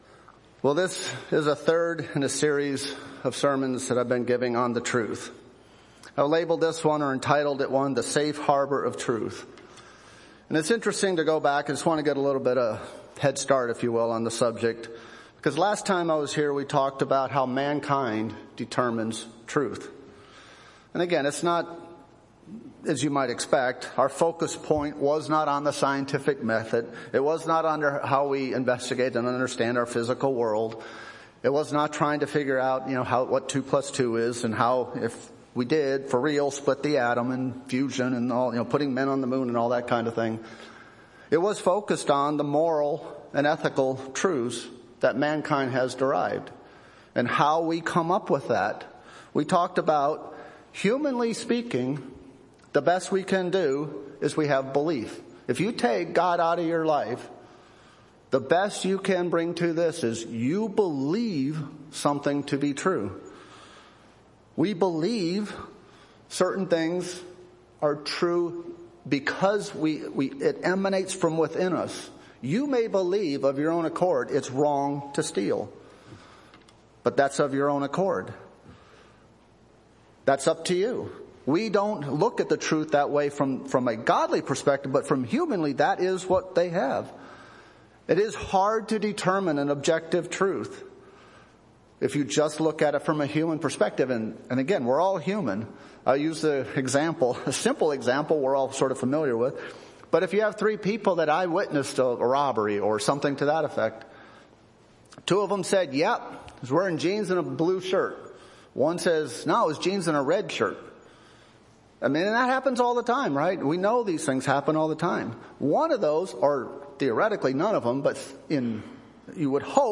How do we defend against the humanistic trend of determining moral, ethical and spiritual truth for ourselves? The third in a series of sermons about truth.